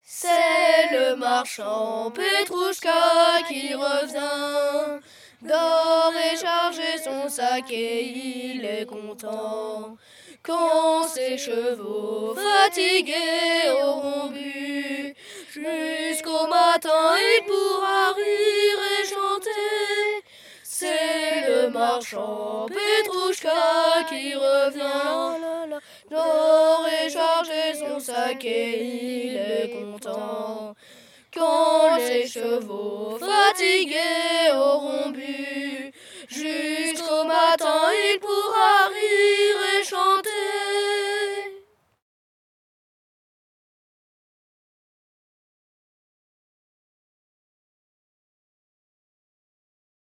Genre : chant
Type : chant de mouvement de jeunesse
Interprète(s) : Les Scouts d'Europe d'Arlon
Lieu d'enregistrement : Arlon
Origine traditionnelle russe.
Enregistrement réalisé dans le cadre de l'enquête Les mouvements de jeunesse en chansons.